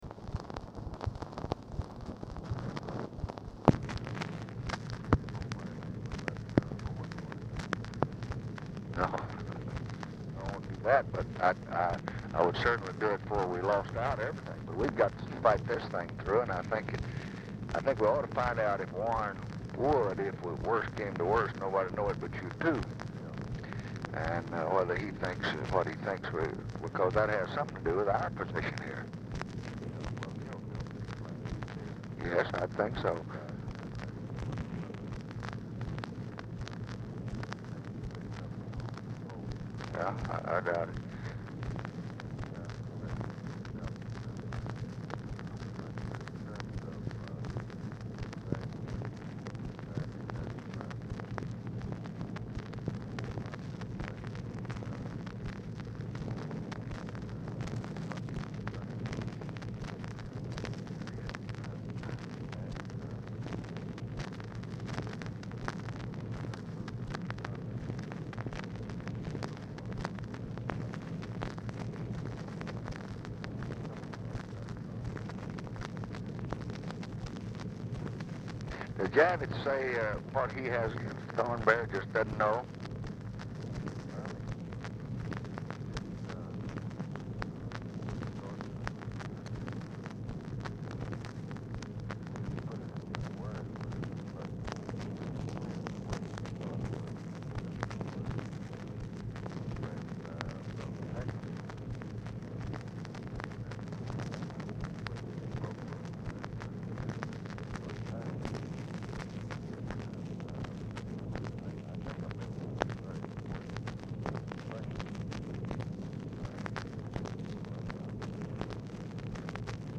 Telephone conversation # 13148, sound recording, LBJ and ABE FORTAS, 6/28/1968, 8:53AM
VERY POOR SOUND QUALITY; FORTAS IS ALMOST INAUDIBLE; RECORDING STARTS AFTER CONVERSATION HAS BEGUN; LBJ SPEAKS TO SOMEONE IN HIS OFFICE DURING CALL;
Dictation belt